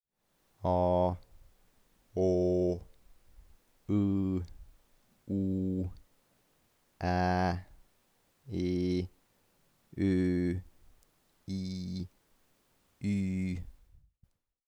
isoleeritud_vokaalid.wav